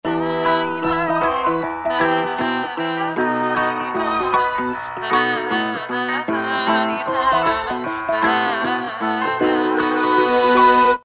رینگتون شاد و بی کلام